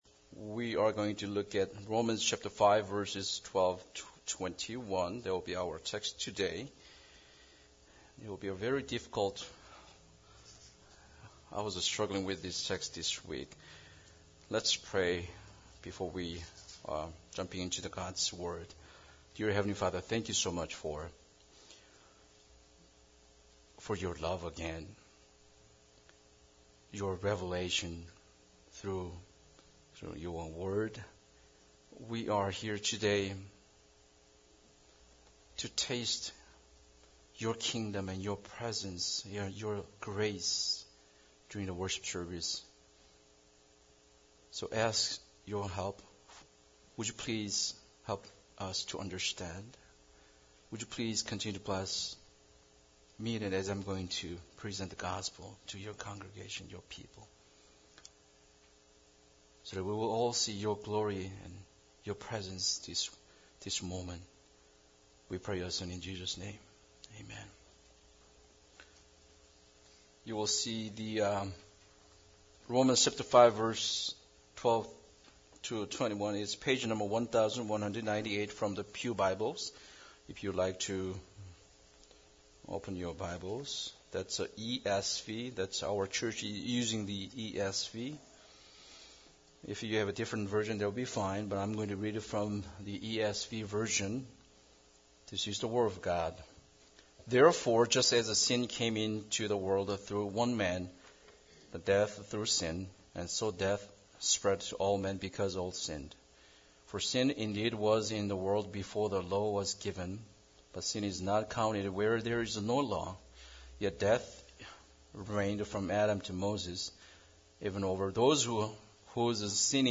Romans 5:12-21 Service Type: Sunday Service Bible Text